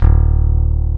EPM E-BASS E.wav